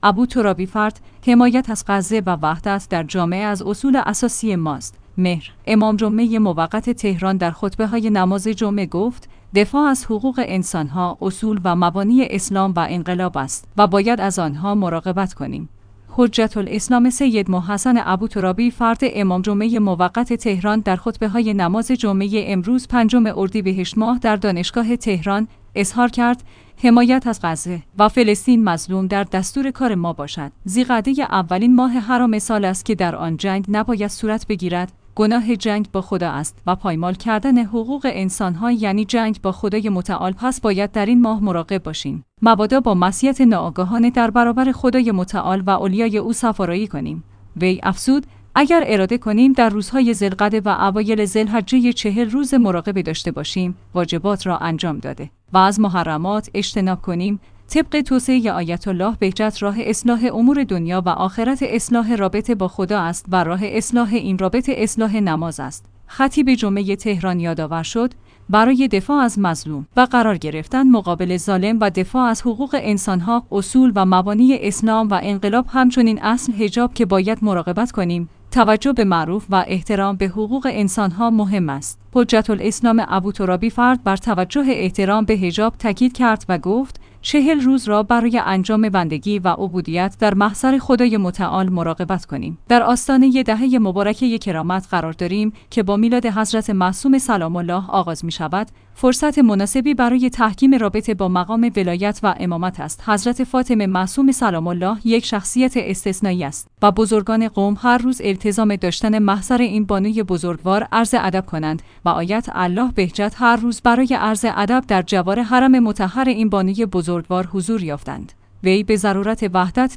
حجت‌الاسلام والمسلمین سید محمدحسن ابوترابی‌فرد در خطبه دوم نماز جمعه ۵ ارد